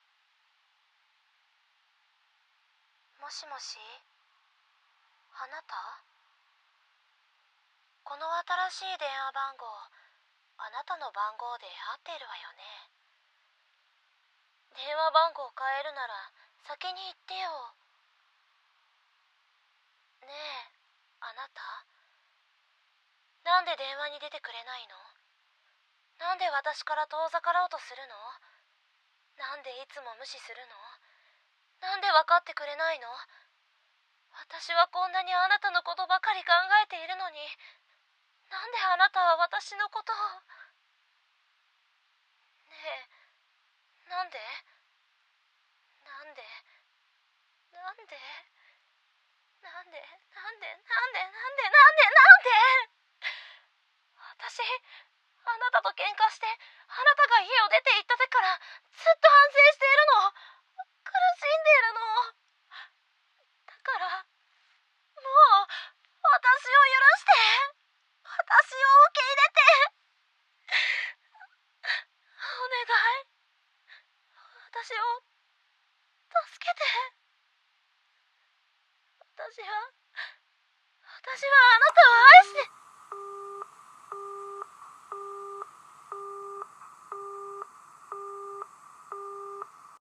【1人声劇】鬱病な妻からの留守番電話【台本】